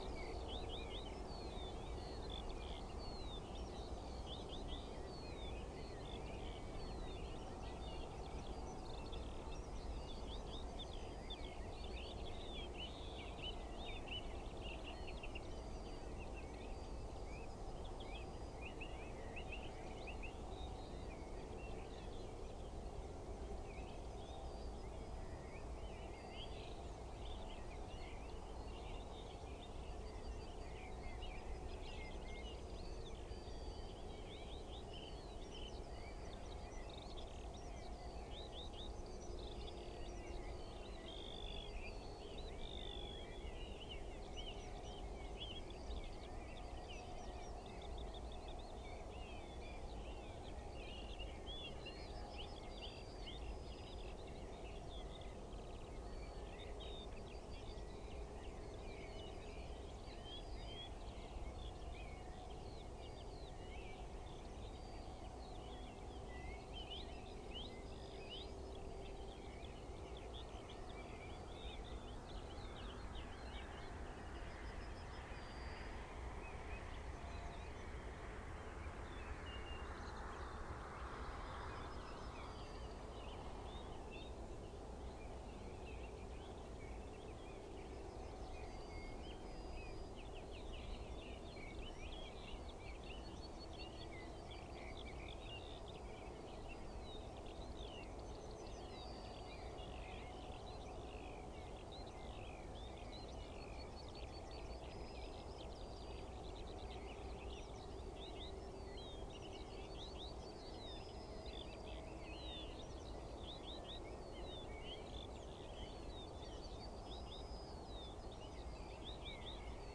Turdus philomelos
Sylvia communis
Emberiza citrinella
Alauda arvensis
Turdus merula
Sylvia atricapilla
Sylvia curruca